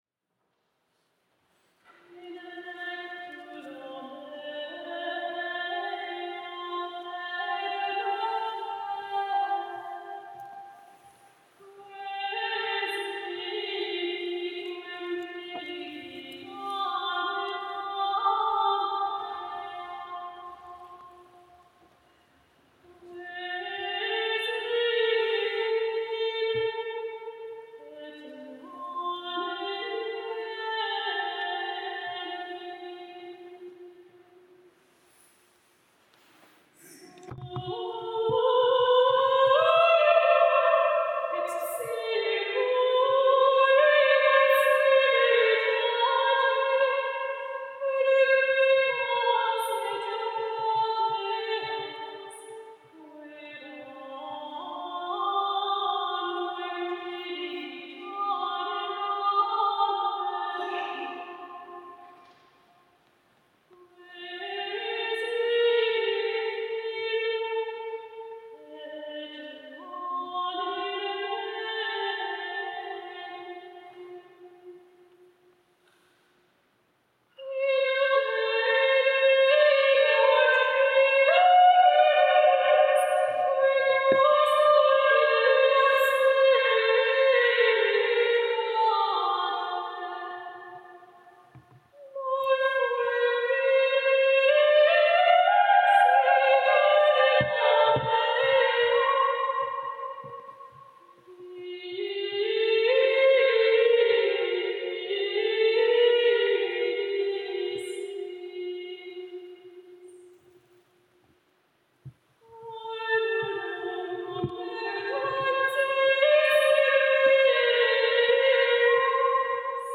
Koncer pasyjny na Kamionku - reportaże i wspomnienia
Każda z wykonawczyń miała sposobność solowego zaprezentowania swojego kunsztu wokalnego.
Każdy głos z osobna i w wielogłosie ukazał nie tylko profesjonalizm śpiewaczy, ale również znajomość specyfiki wokalistyki dawnych wieków.
Anima mea liquefacta est/Descendi in hortum meum/ALMA [redemptoris mater] – motet z XIII wieku (Francja), In lectulo meo – antyfona z XV wieku (Tegernsee, Bawaria),